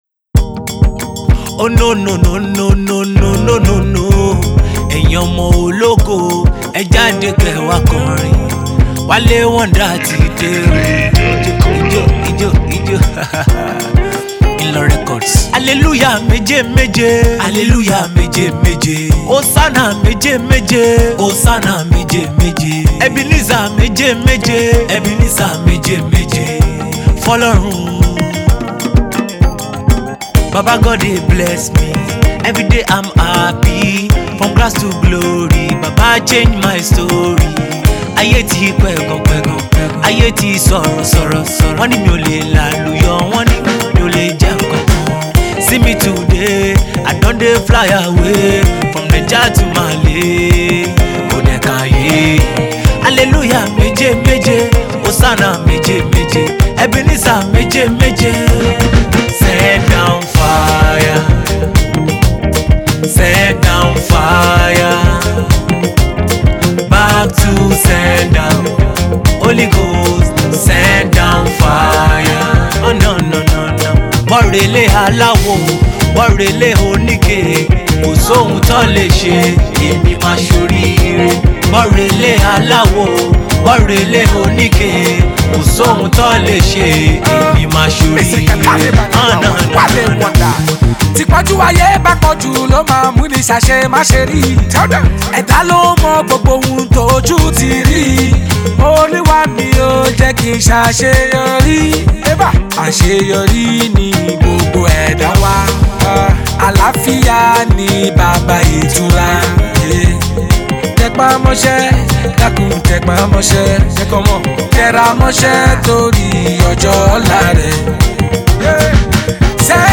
a mid-tempo, conscious and yet groovy tune